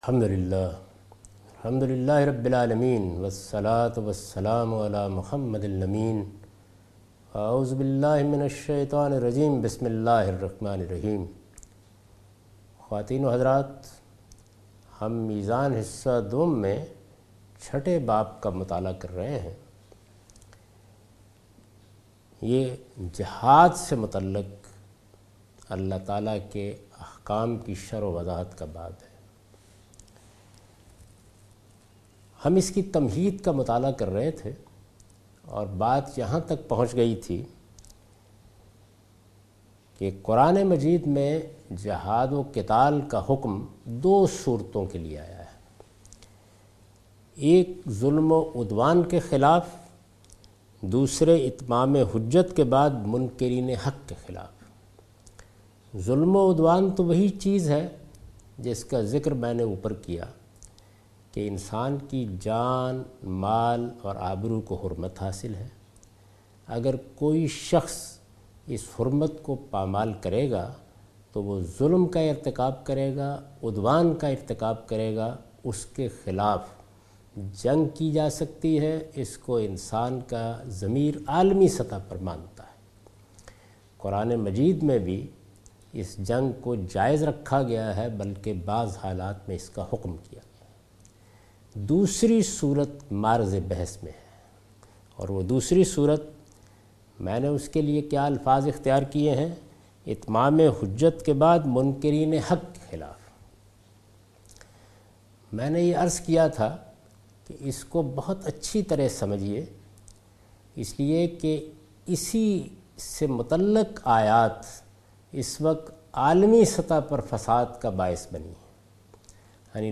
A comprehensive course taught by Javed Ahmed Ghamidi on his book Meezan.